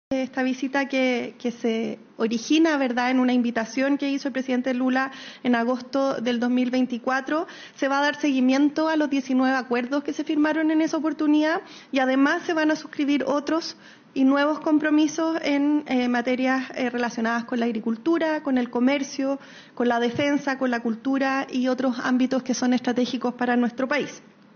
CUNA-VOCERA.mp3